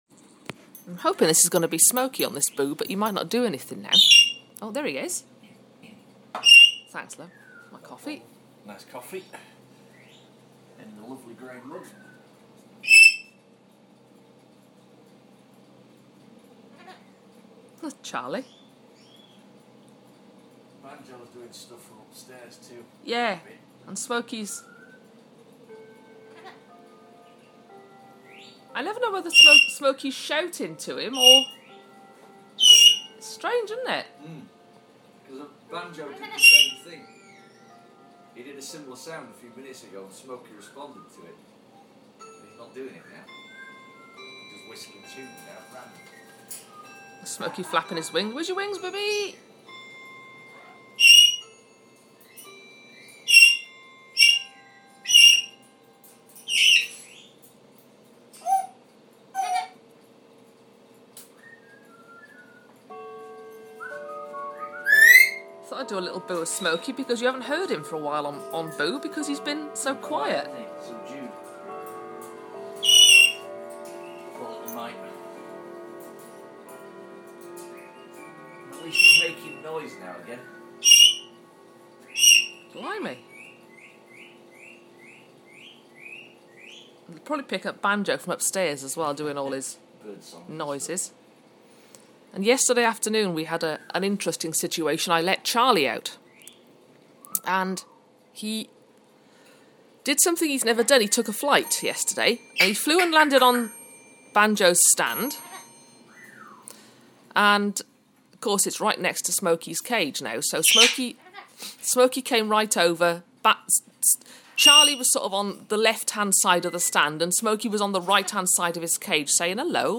A very noisy Smokey bird.